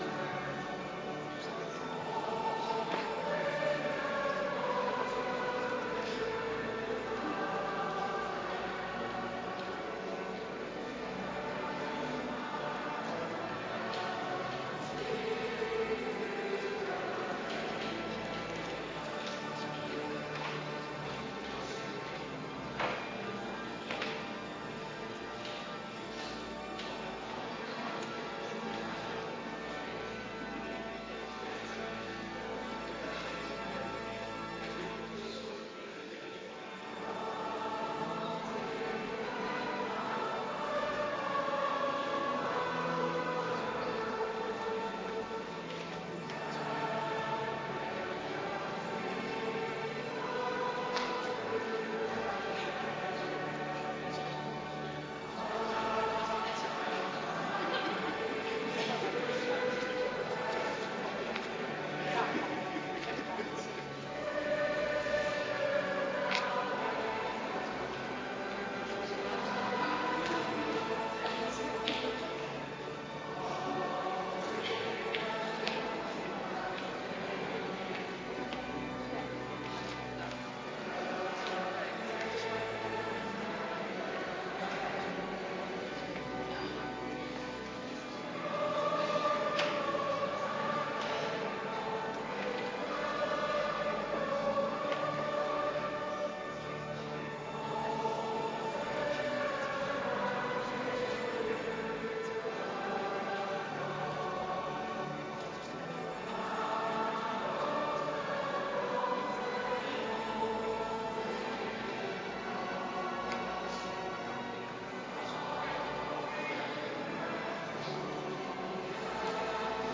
Kerkdiensten
Bediening Heilig Avondmaal